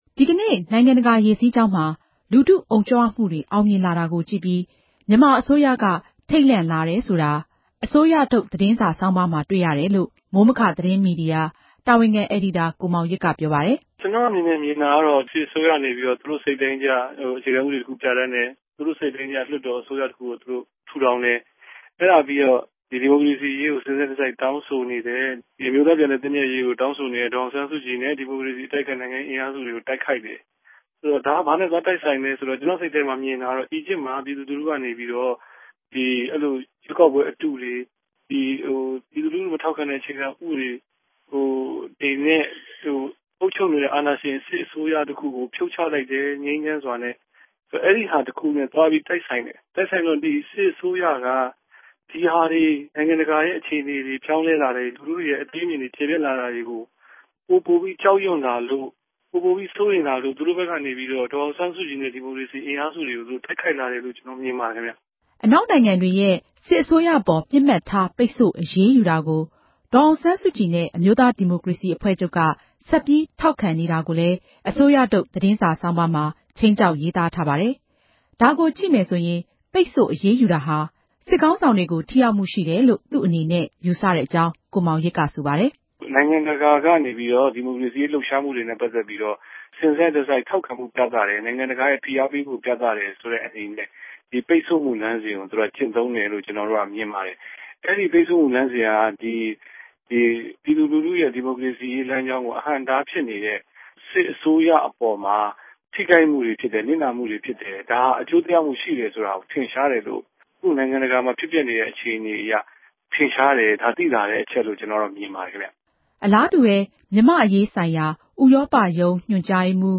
စုစည်းတင်ပြချက်